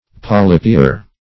polypier - definition of polypier - synonyms, pronunciation, spelling from Free Dictionary Search Result for " polypier" : The Collaborative International Dictionary of English v.0.48: Polypier \Po`ly`pier"\, n. [F.]